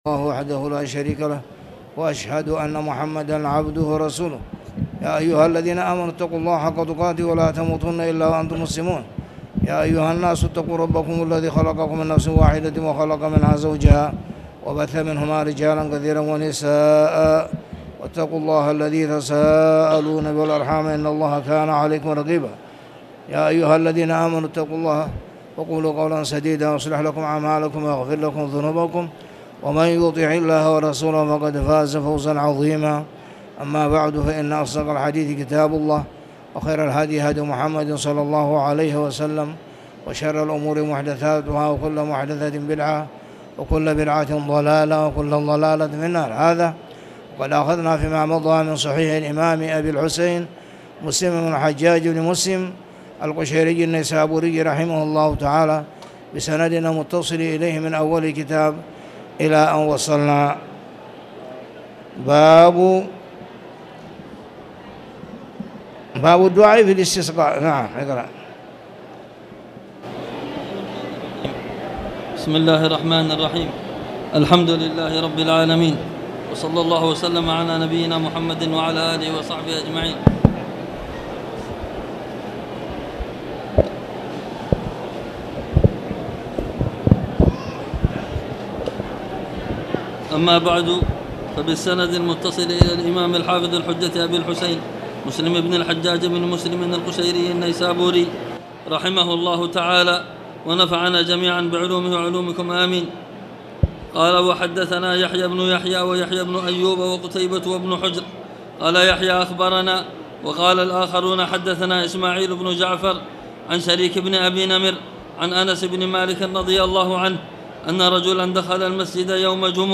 تاريخ النشر ١٢ ذو القعدة ١٤٣٧ المكان: المسجد الحرام الشيخ